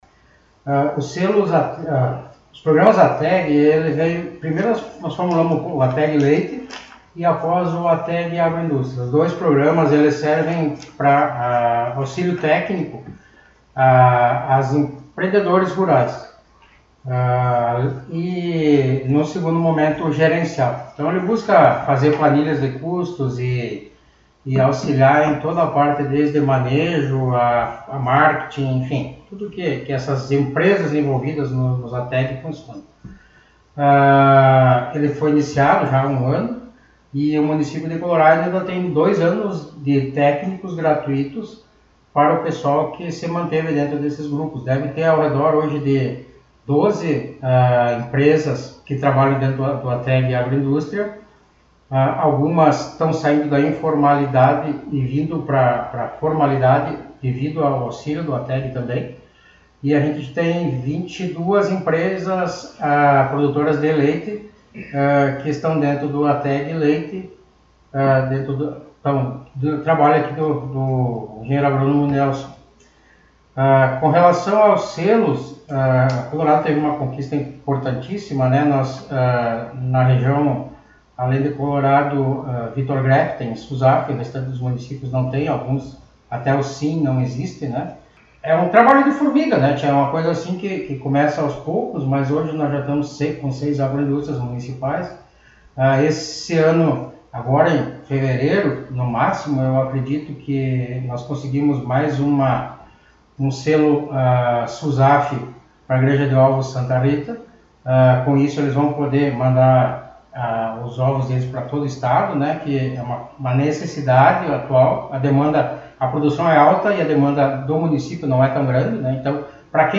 Secretário Municipal de Agricultura e funcionários concederam entrevista